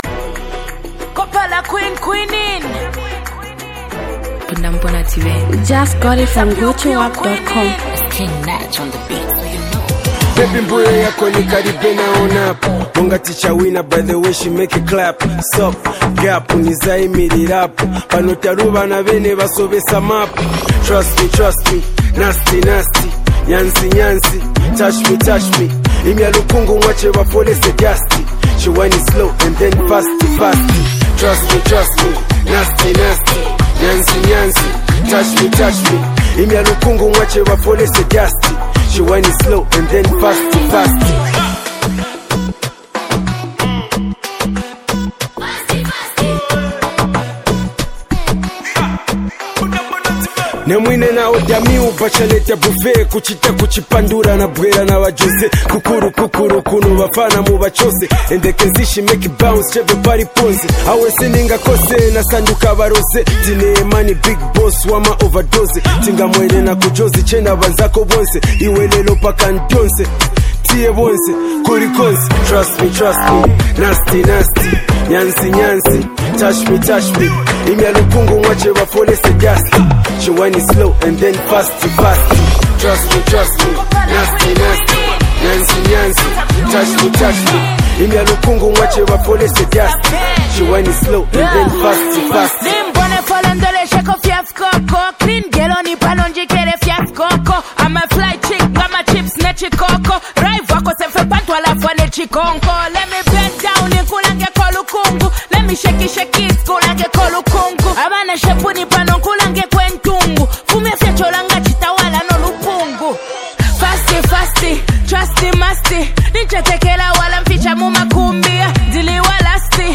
Zambian Mp3 Music
Zambian multi-talented rapper and comedian